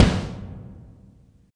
35 KICK.wav